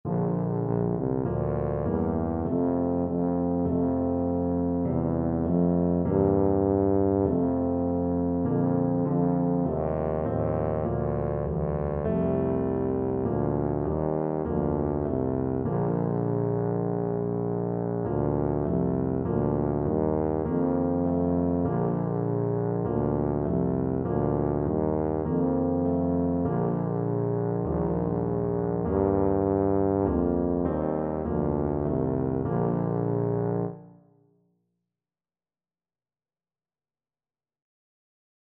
Tuba
Bb major (Sounding Pitch) (View more Bb major Music for Tuba )
4/4 (View more 4/4 Music)
Bb2-G3
Classical (View more Classical Tuba Music)